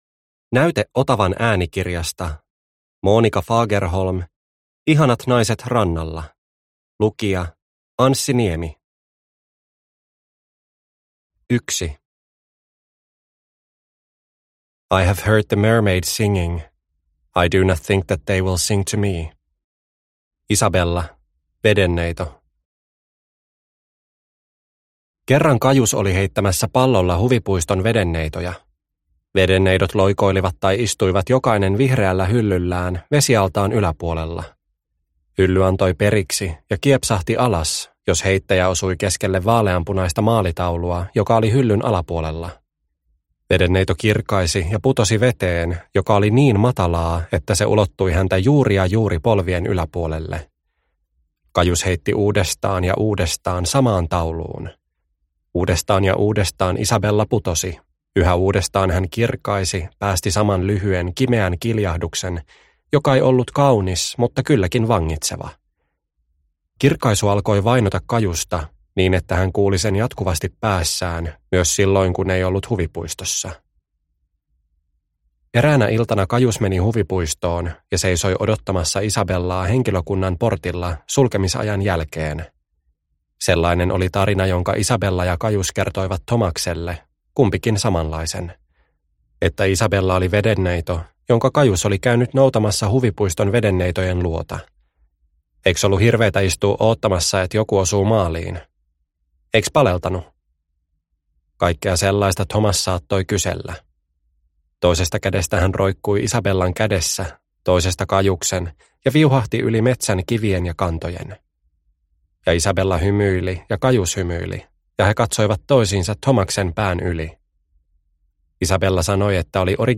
Ihanat naiset rannalla – Ljudbok – Laddas ner